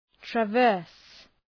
Προφορά
{‘trævərs}